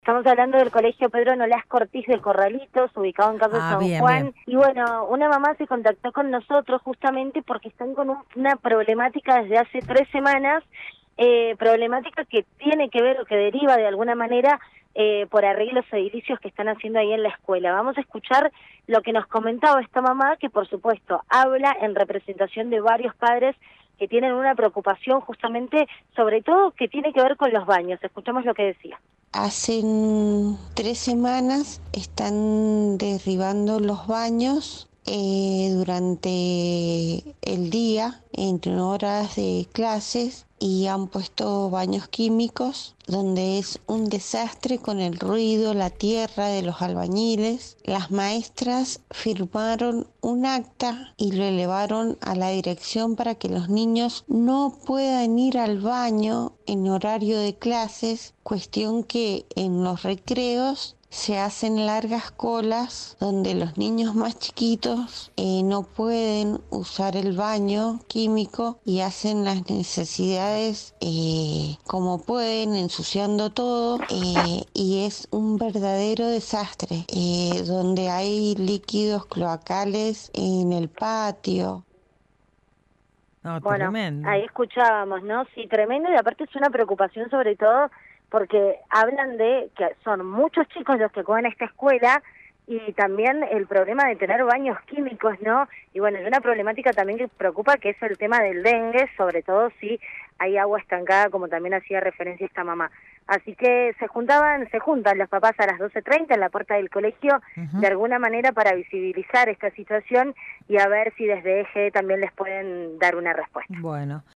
LVDiez - Radio de Cuyo - Móvil de LVDiez- reclamo por Escuela Pedro Nolasco Ortiz de Corralitos